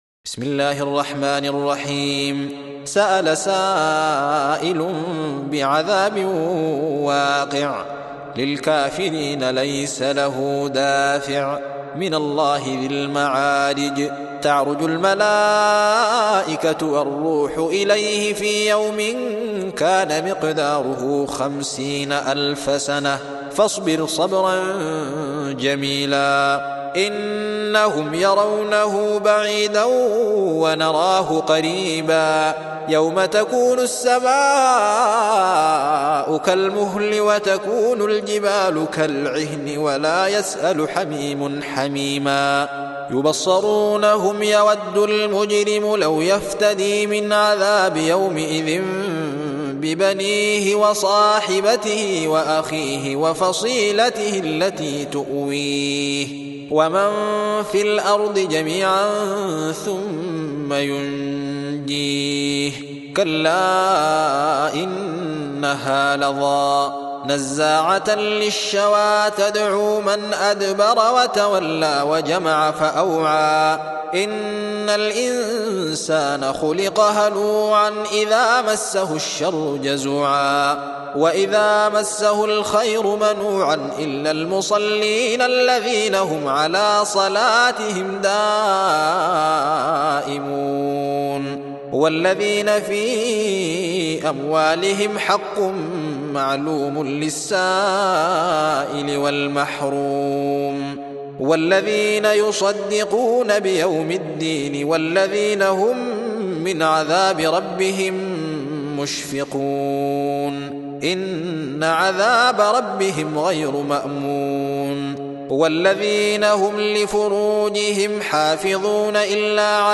70. Surah Al-Ma'�rij سورة المعارج Audio Quran Tarteel Recitation
Surah Repeating تكرار السورة Download Surah حمّل السورة Reciting Murattalah Audio for 70.